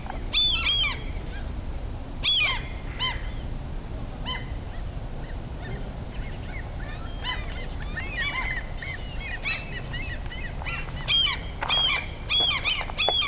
Abb. 14:  Möven, klare einzelne Frequenzen übereinander, ansteigend und fallend, lineare Frequenzachse, beide Tiere schreien zeitlich versetzt